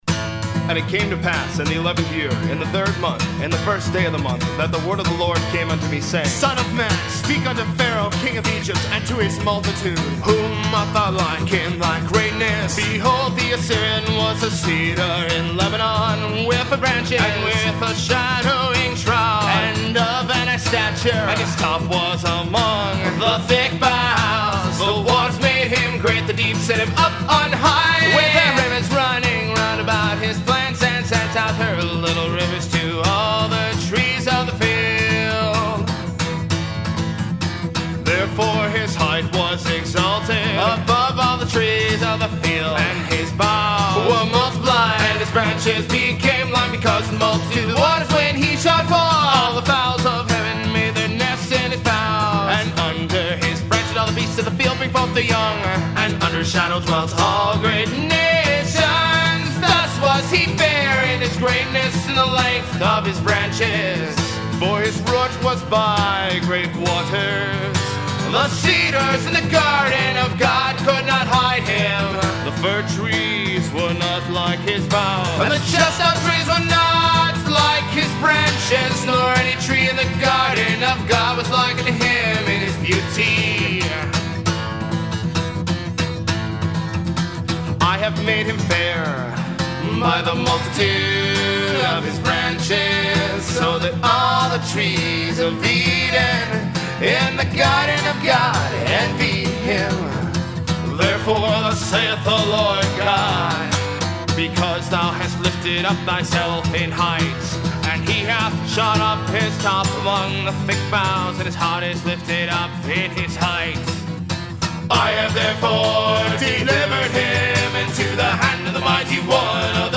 Powerful aggressive folk and rock